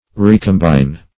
Recombine \Re`com*bine"\ (r?`k?m*b?n"), v. t.